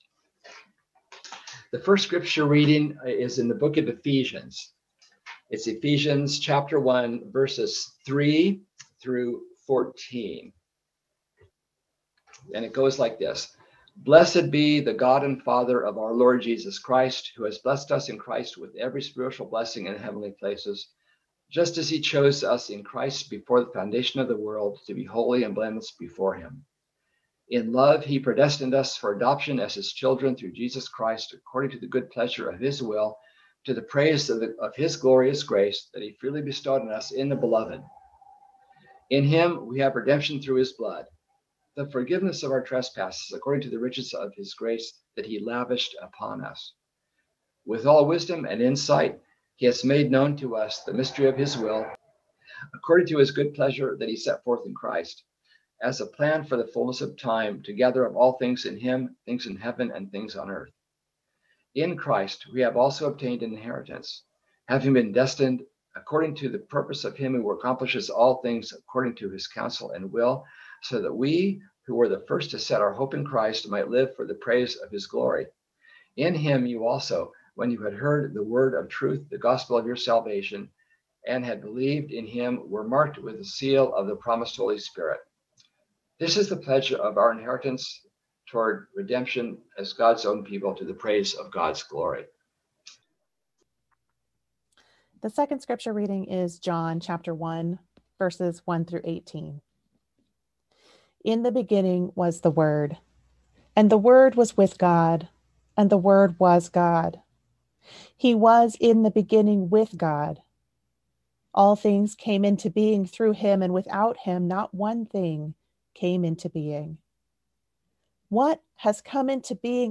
Listen to the most recent message from Sunday worship at Berkeley Friends Church